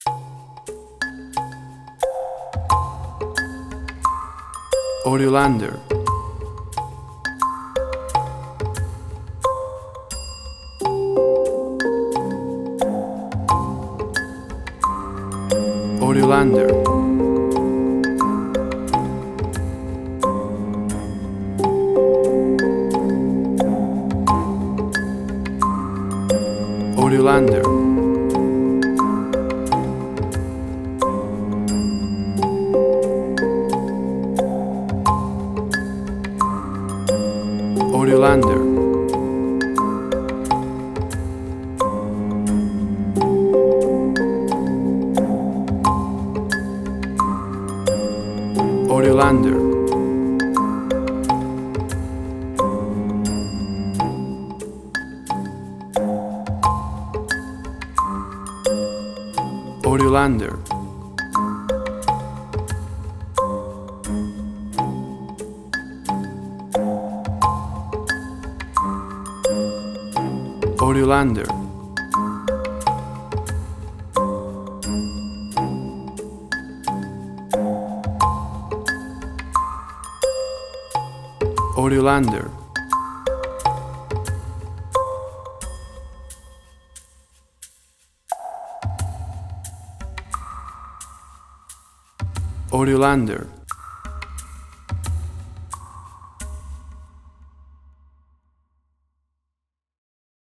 WAV Sample Rate: 24-Bit stereo, 48.0 kHz
Tempo (BPM): 88